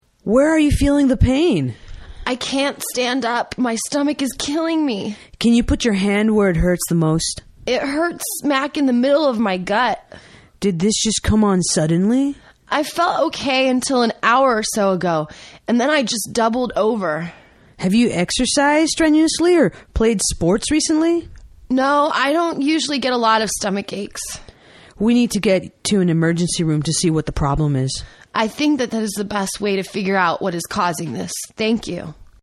原版英语对话：A Medical Emergency(3) 听力文件下载—在线英语听力室